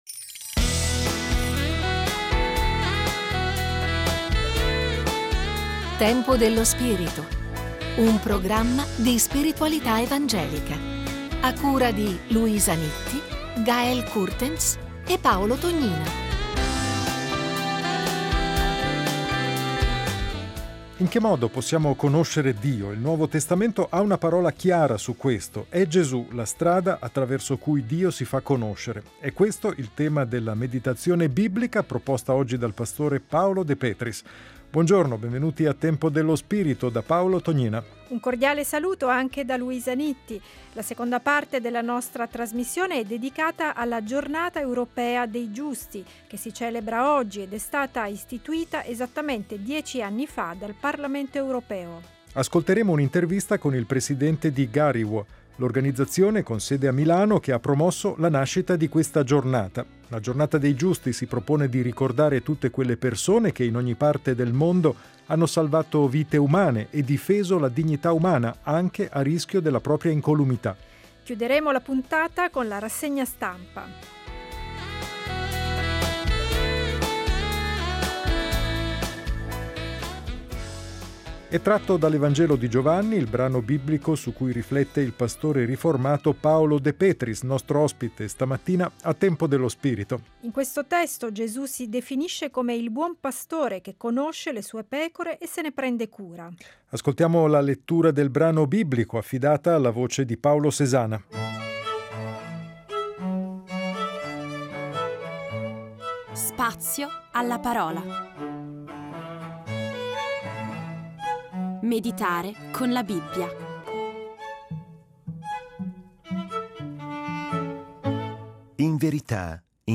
Si celebra oggi la Giornata europea dei Giusti, per ricordare coloro che in ogni parte del mondo hanno salvato vite umane in tutti i genocidi e difeso la dignità umana mettendo a rischio la propria vita. Intervista
Scopri la serie Tempo dello spirito Settimanale di spiritualità evangelica.